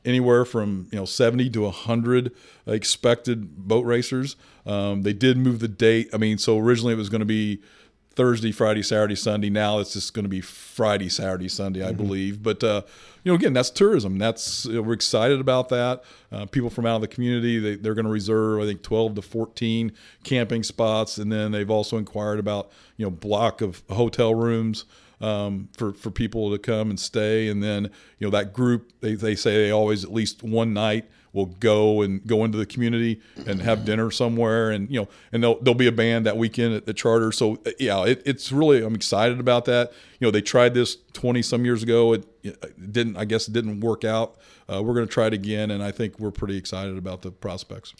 We are now less than a month away from the NBRA Boat Racing evening at Vandalia Lake. Speaking during our weekly podcast “Talking about Vandalia,” Mayor Doug Knebel says he’s excited about the event coming to Vandalia Lake September 5th through 7th.